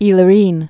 (ēlə-rēn, ĭ-lôrən)